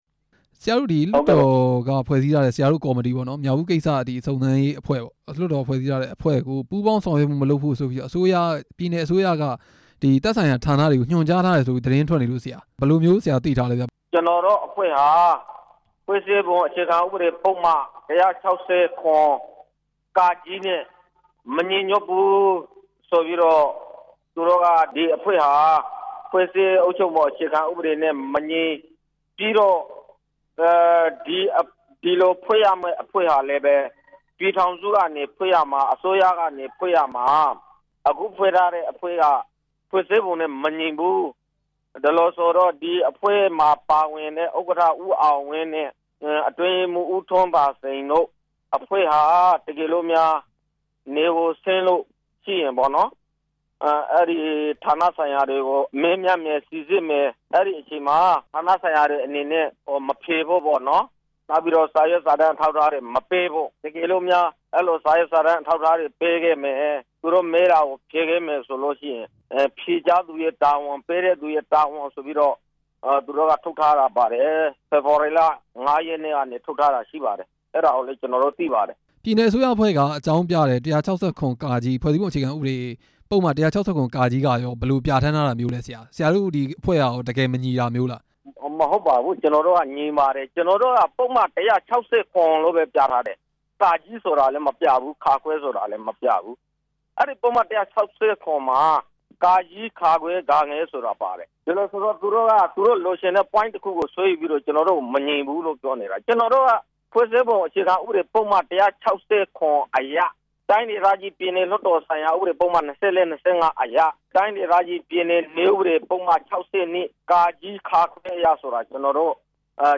မြောက်ဦး စုံစမ်းရေးအဖွဲ့ အတွင်းရေးမှူး ဦးထွန်းသာစိန် နဲ့ မေးမြန်းချက်